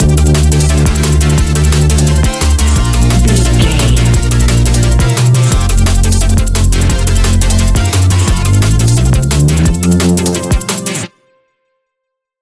Ionian/Major
A♯
electronic
techno
trance
synths
synthwave